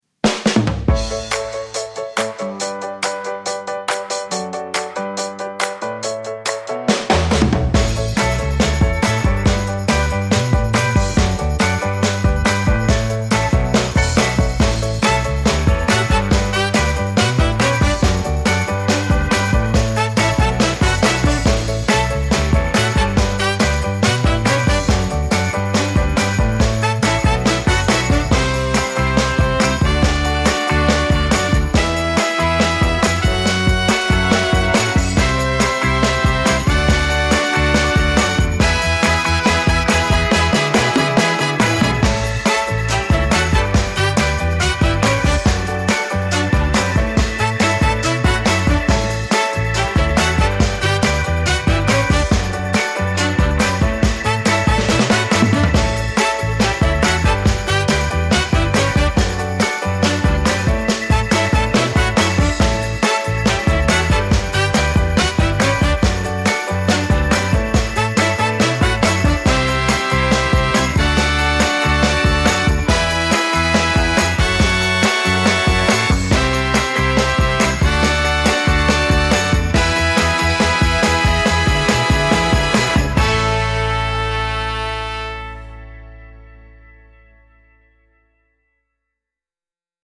classic motown soul celebration with horns, tambourine and walking bass